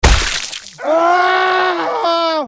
звуки , крики